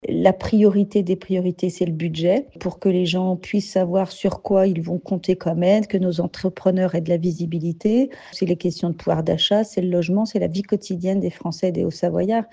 Le budget affiché comme grande priorité, c’est aussi l’avis de la députée haut-savoyarde Véronique Riotton.